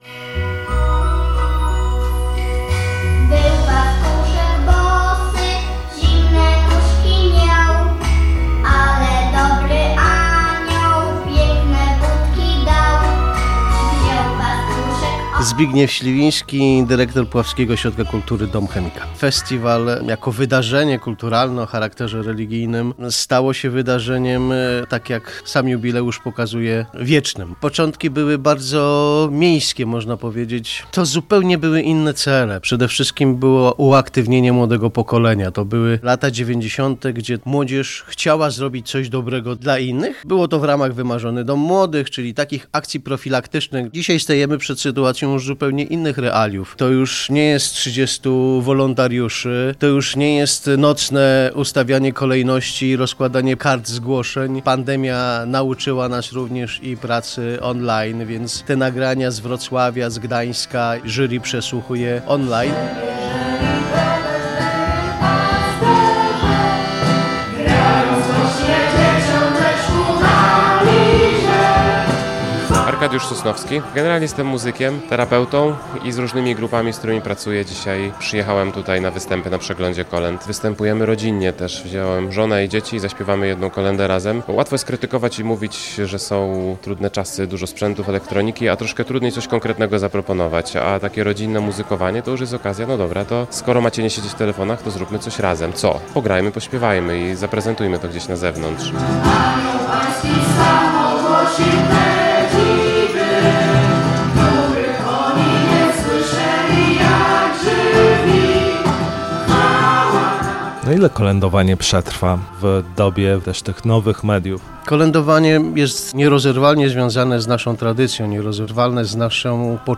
Ponad 2 tysiące kolędników z 14. województw bierze udział w jubileuszowej – 30. edycji Ogólnopolskiego Festiwalu Kolęd w Puławach. Jury wysłucha ponad 300 różnych prezentacji solistów, zespołów wokalnych, wokalno-instrumentalnych, chórów oraz muzykujących rodzin.